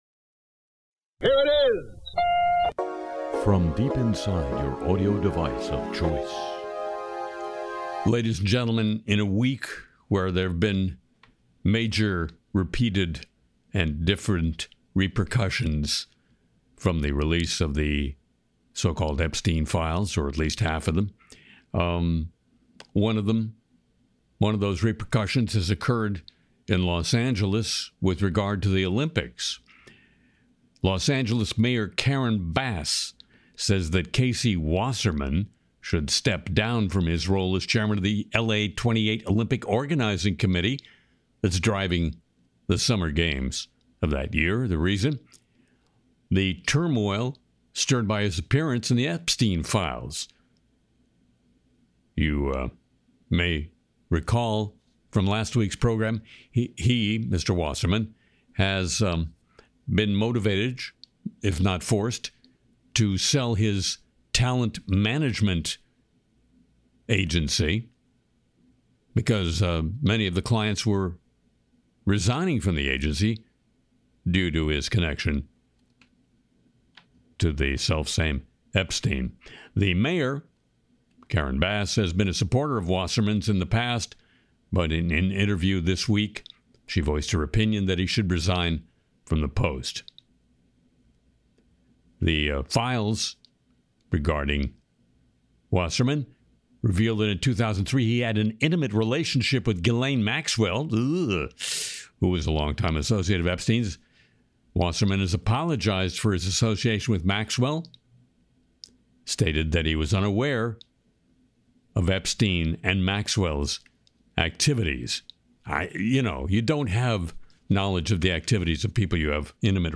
Harry sings “The Lolita Express,” tracks Epstein Files news, lampoons Trump Court, and looks at chatbots stumbling over basic real-world tasks.